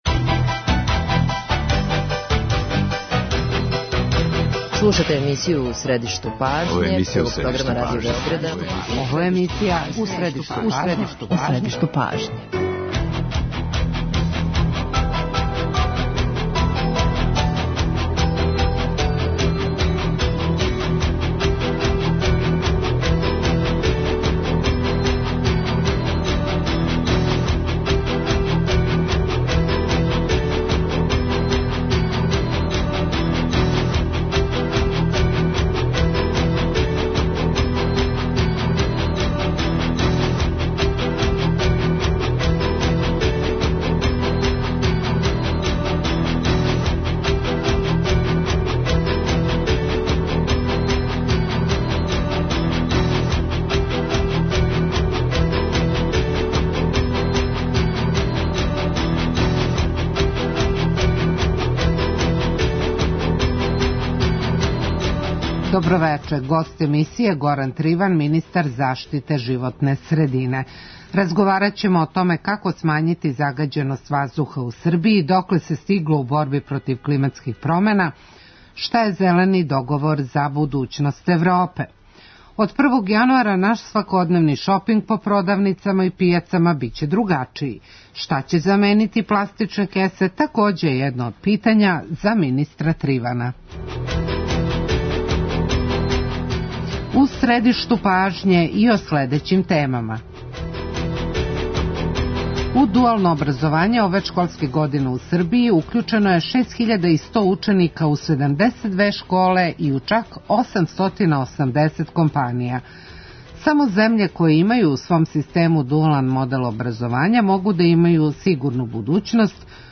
Гост емисије је Горан Триван, министар заштите животне средине.